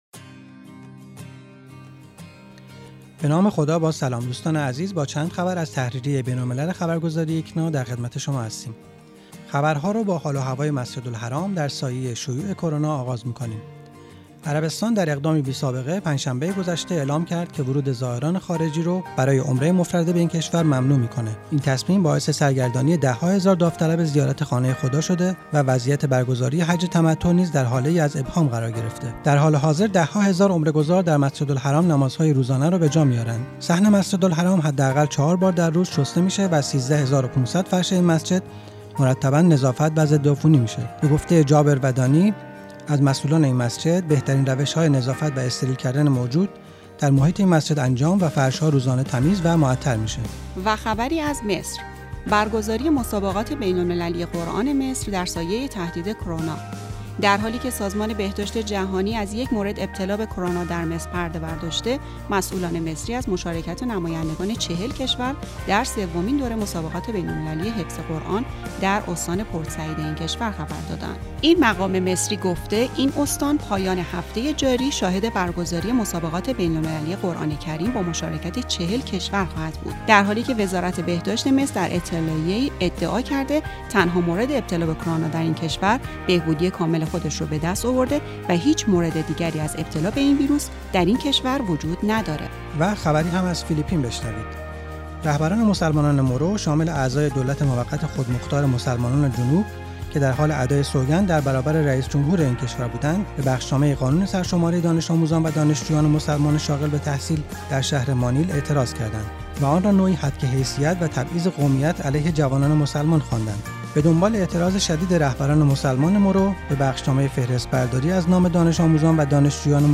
تحریریه بین‌الملل ایکنا اخبار جهان اسلام در هفته گذشته را در قالب یک پادکست مرور کرده است.